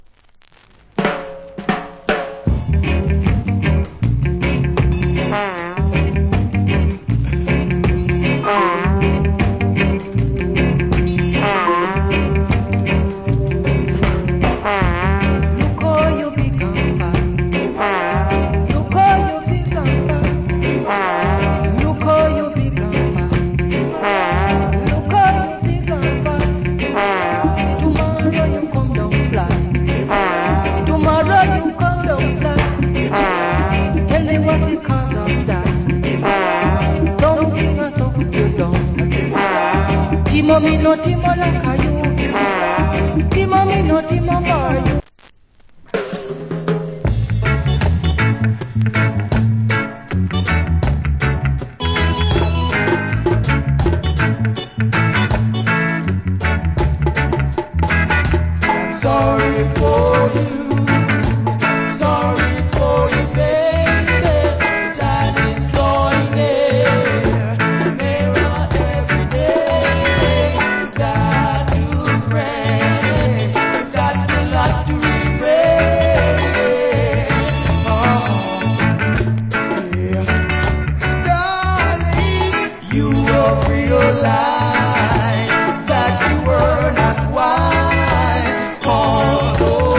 Wicked Rock Steady Vocal.